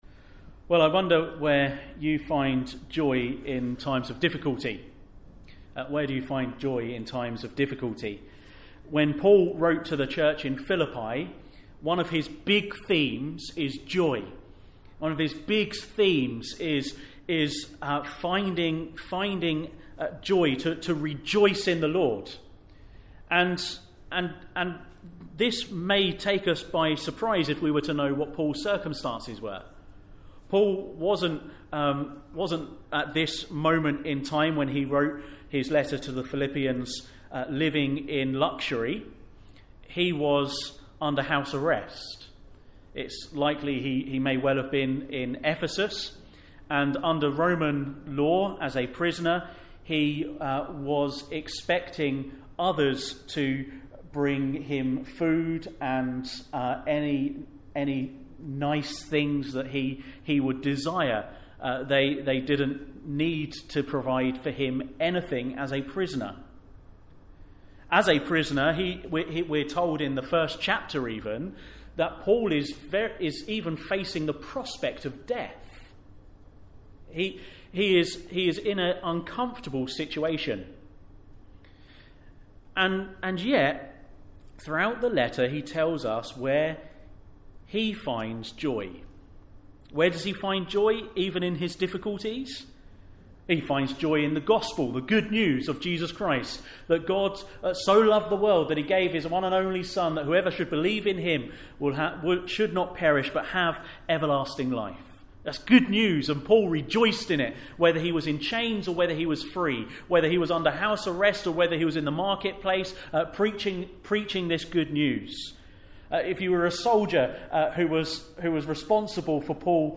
Sunday Morning Passage: Philippians 1:1-11 Service Type: Sunday Morning « Paul’s New Vision Of Jesus Jonah